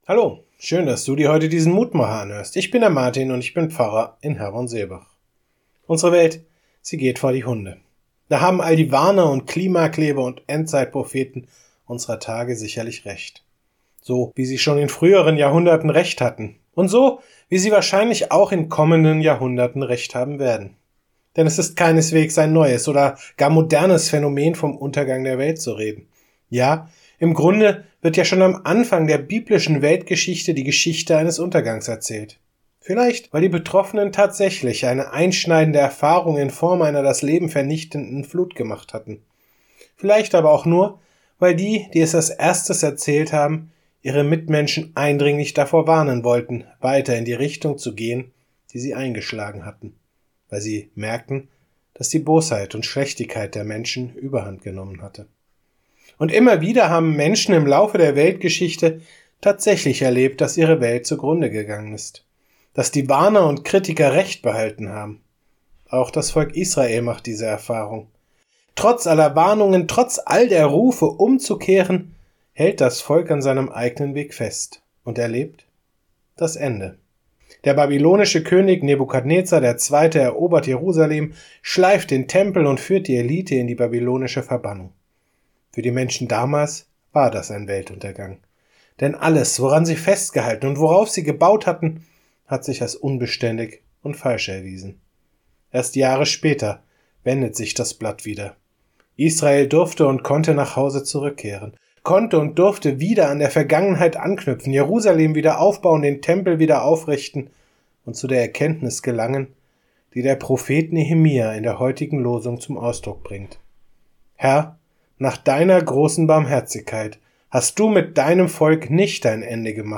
Kurzer täglicher Andachtsimpuls zu Losung oder Lehrtext des Herrnhuter Losungskalender